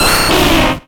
Cri d'Herbizarre dans Pokémon X et Y.